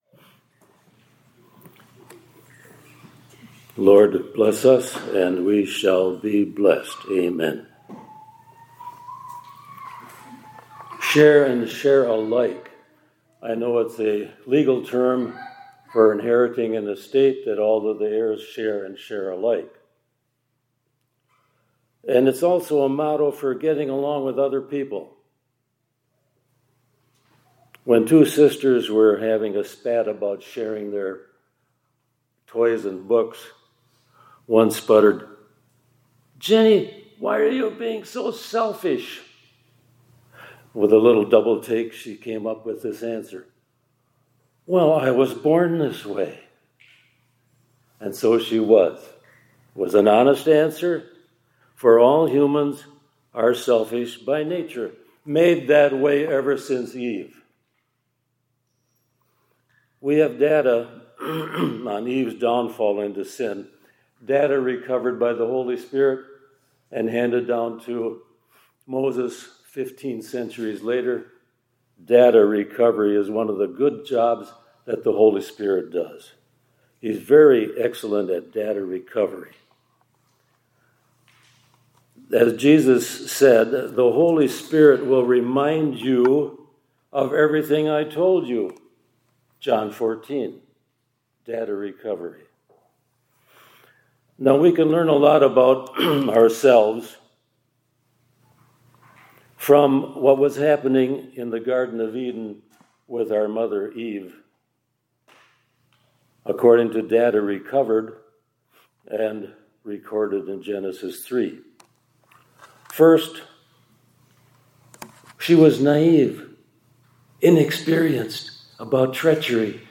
2026-03-05 ILC Chapel — Jesus’ Temptation Was Important —…